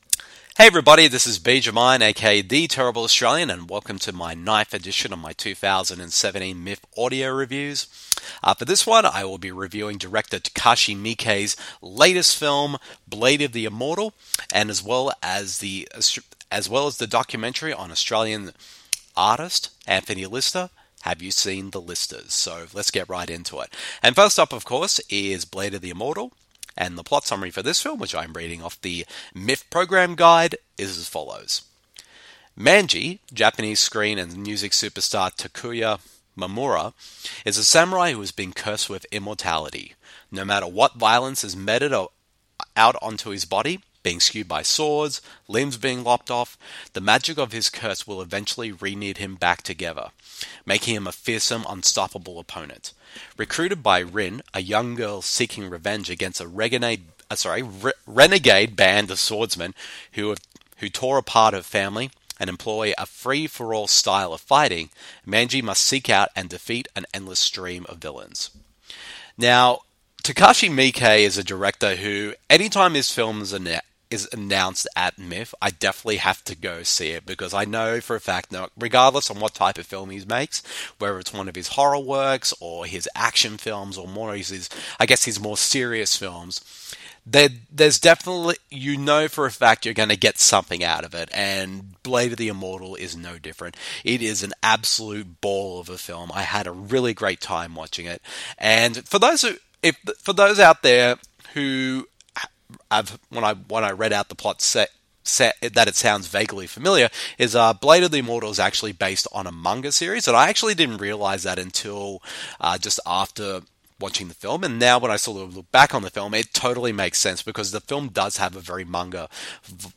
Welcome to the 9th edition of my 2017 Melbourne International Film Festival (a.k.a. MIFF) audio reviews. In the one, I share my thoughts on director Takashi Miike's latest film BLADE OF THE IMMORTAL and documentary on Australian artist Anthony Lister HAVE YOU SEEN THE LISTERS?.